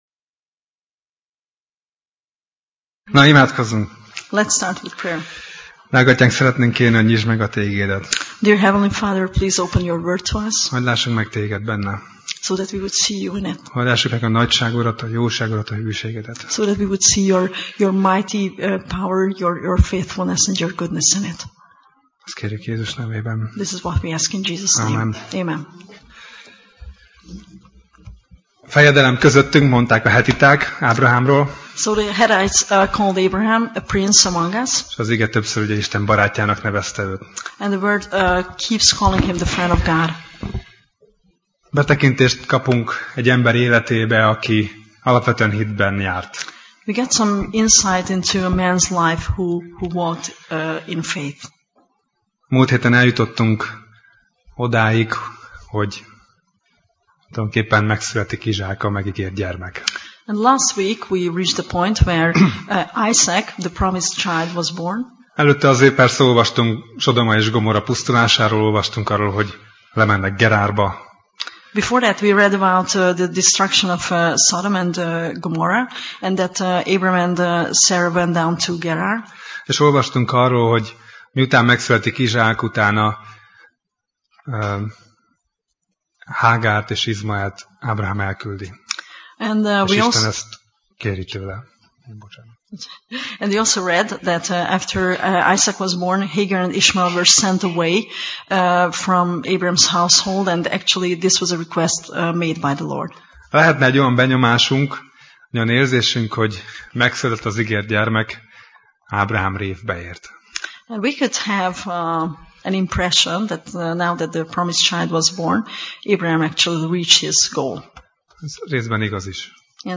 Tematikus tanítás Passage
Alkalom: Szerda Este